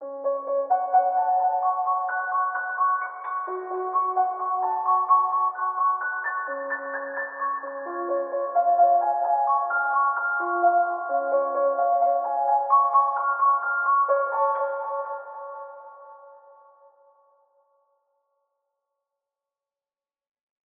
AV_Piano_BrokenKeys_130bpm_F#m
AV_Piano_BrokenKeys_130bpm_Fm.wav